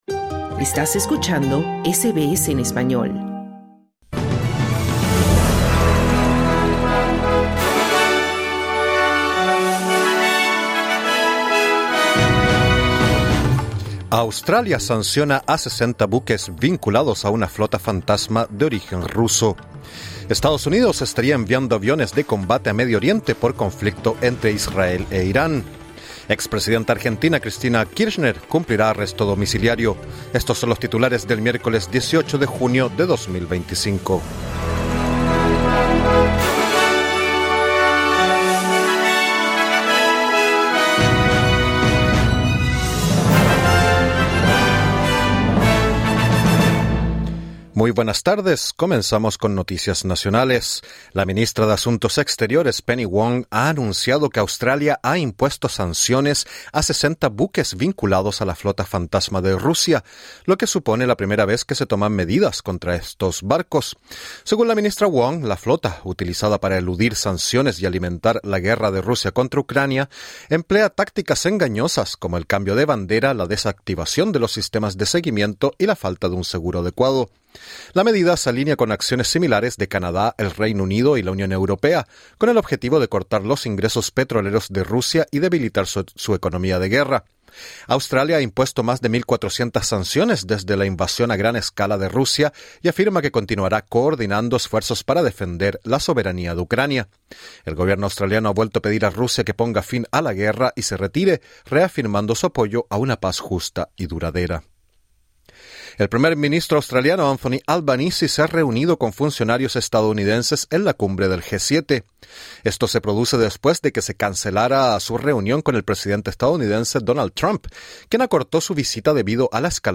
Noticias SBS Spanish | 18 junio 2025